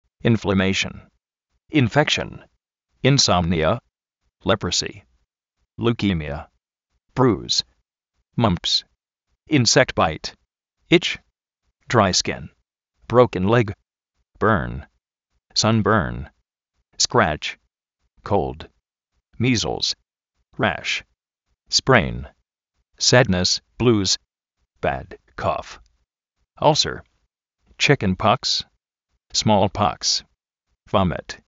inflaméishn
infékshn
insómnia
brú:s
kóuld
vómit